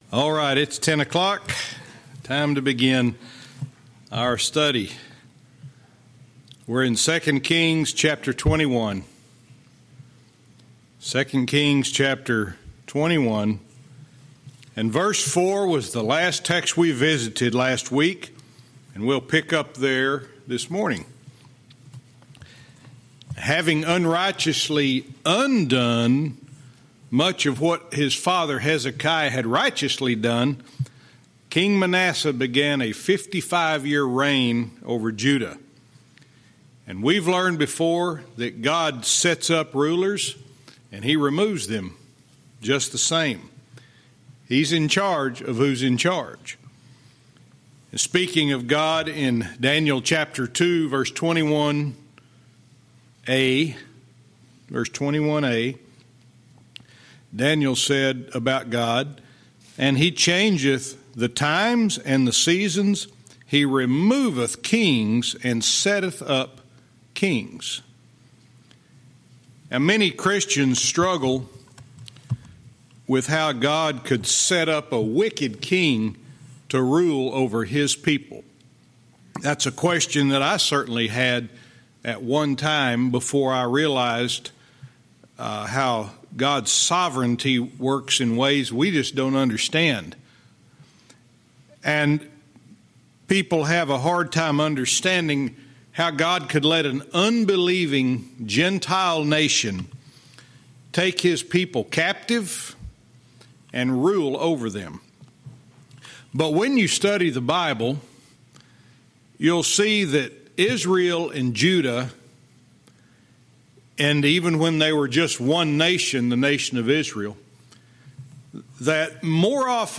Verse by verse teaching - 2 Kings 21:4-7